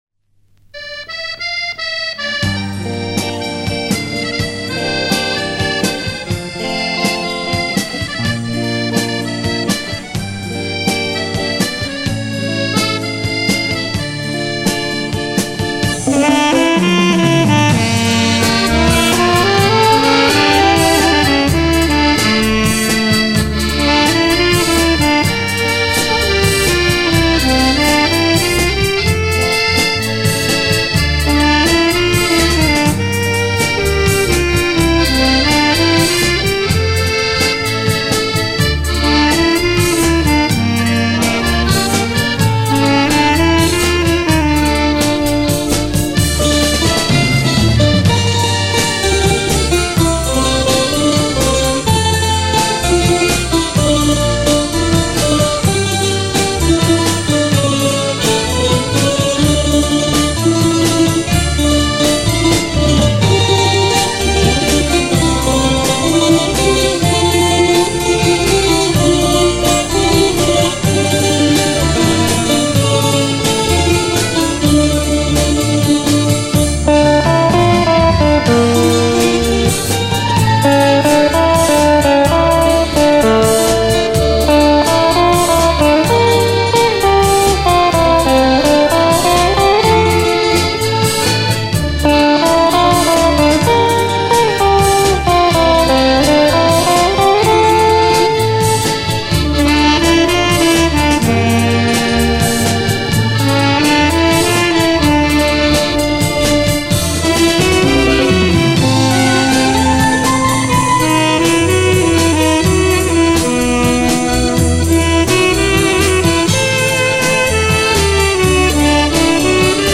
Вальс.mp3